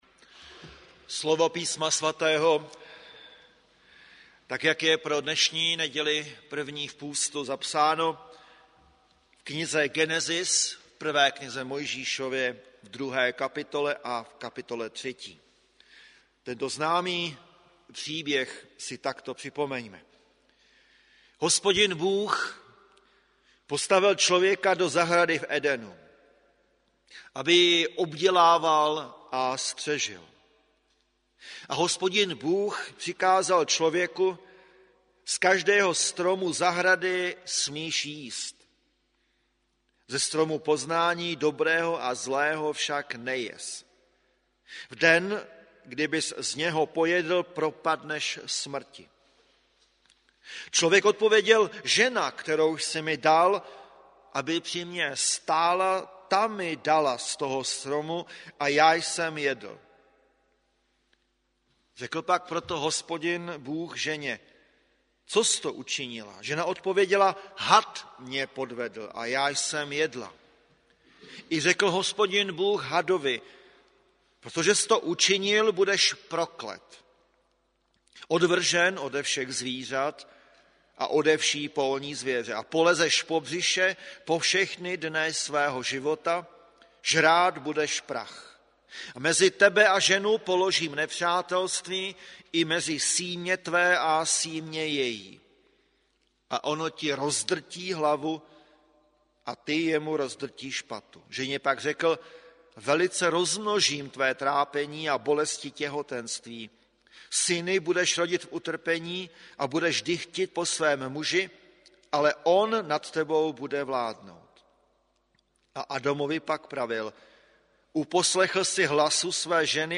Bohoslužby 1. 3. 2020 • Farní sbor ČCE Plzeň - západní sbor